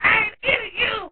File:Clock Tower parrot.ogg - The Cutting Room Floor
File File history File usage Metadata Clock_Tower_parrot.ogg  (Ogg Vorbis sound file, length 1.1 s, 48 kbps) This file is an audio rip from a(n) SNES game.
Clock_Tower_parrot.ogg.mp3